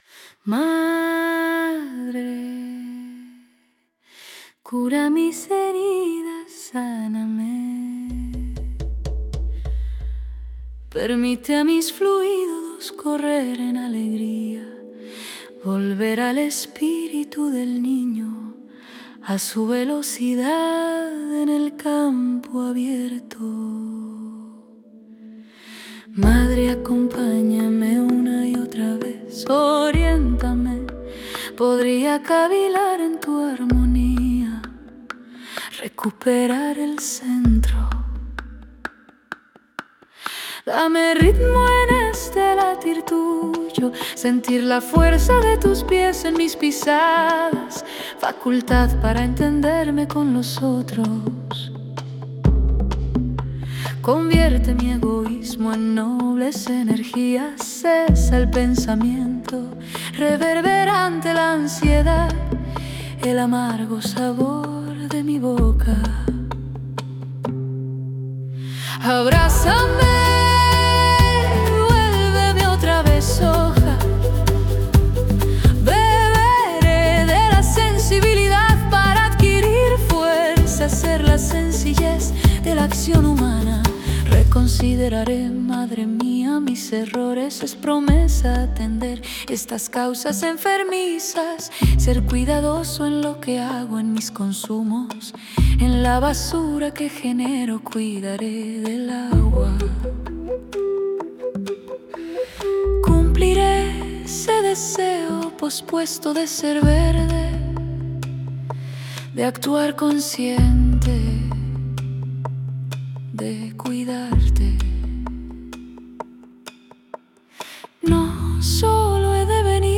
La versión cantada amplifica este mensaje y lo vuelve experiencia sonora: un latir compartido que recuerda que nuestro poder no es abstracto, sino profundamente humano, sensible y presente en cada acto de cuidado hacia el planeta que habitamos.